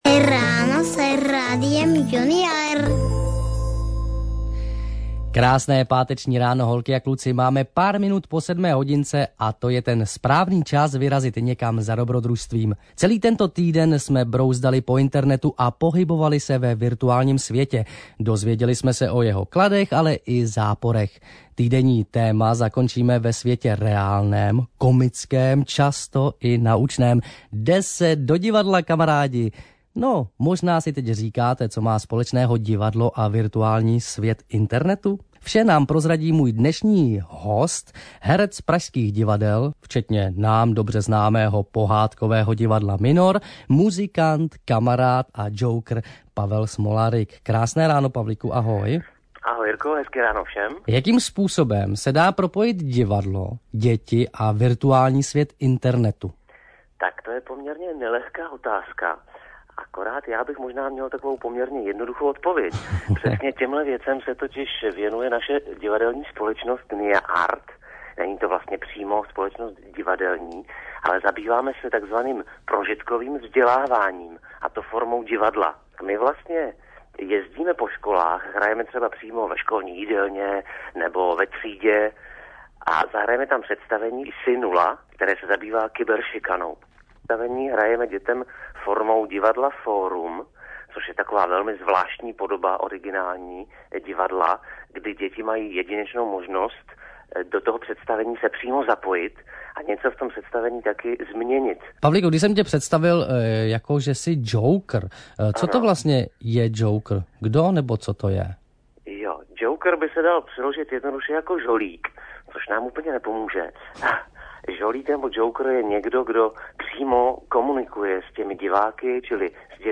Rozhovor-Rádio-Junior.mp3